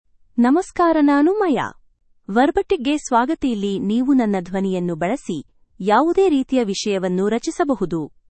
Maya — Female Kannada (India) AI Voice | TTS, Voice Cloning & Video | Verbatik AI
MayaFemale Kannada AI voice
Maya is a female AI voice for Kannada (India).
Voice sample
Maya delivers clear pronunciation with authentic India Kannada intonation, making your content sound professionally produced.